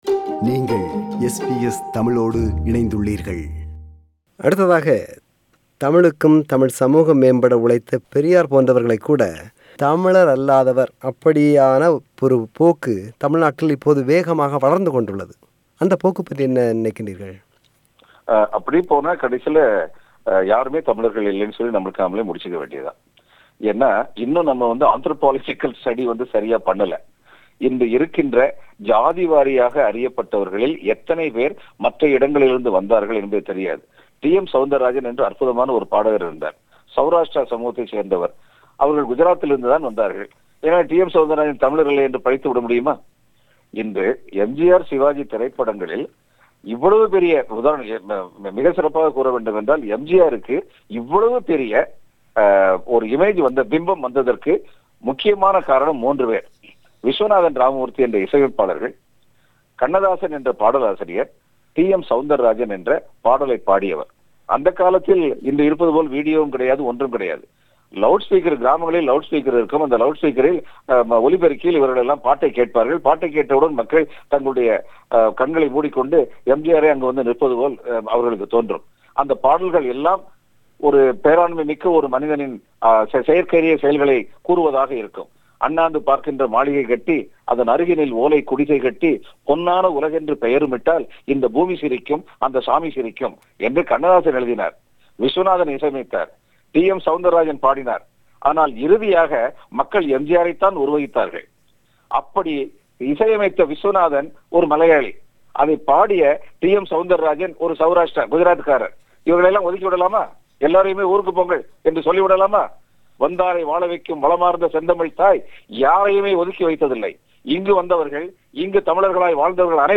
அவரோடு ஒரு மனம் திறந்த உரையாடல்.